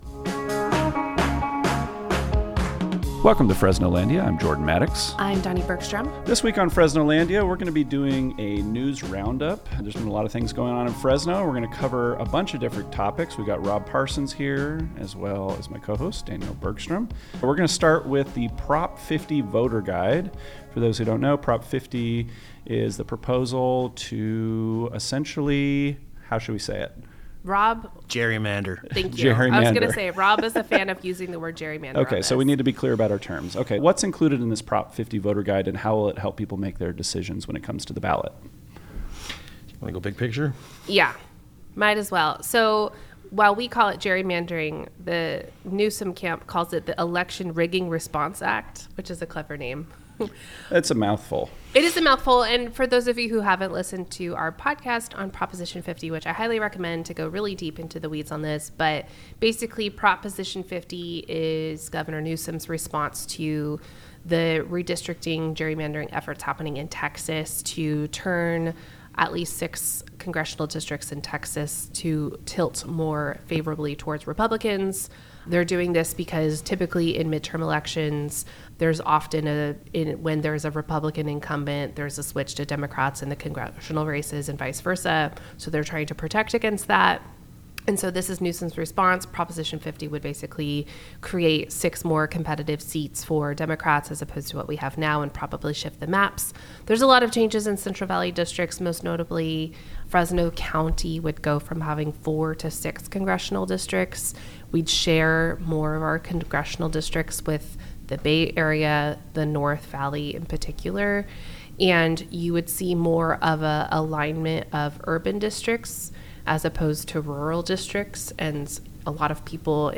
for a wide-ranging news roundup on the biggest issues shaping Fresno and the Central Valley.